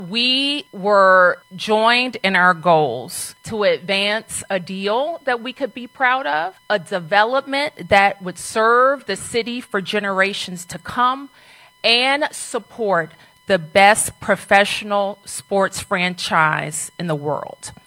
The Washington Commanders are coming home to D.C.  At a press conference this morning, DC Mayor Muriel Bowzer was joined by the football team’s owner Josh Harris, NFL Commissioner Roger Goodell and various Washington football icons.